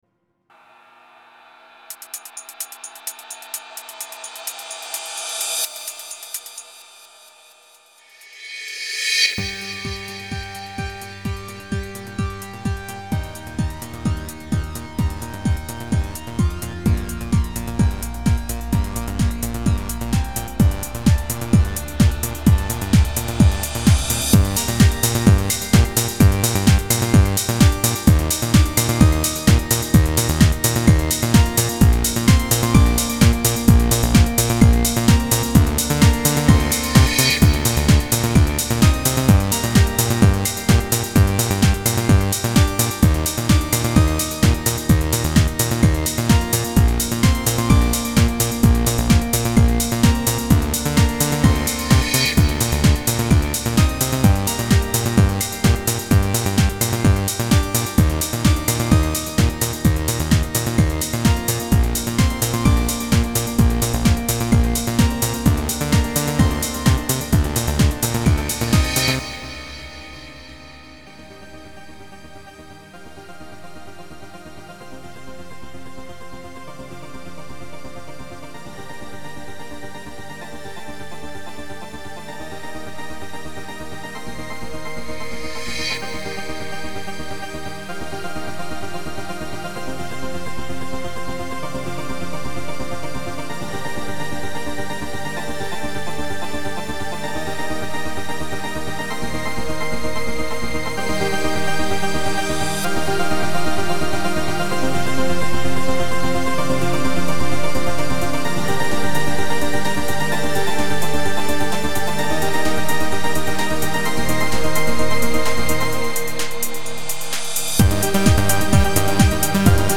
От себя добавлю, что трек очень и очень хорош своей мелодией.
Сэмплы: Свои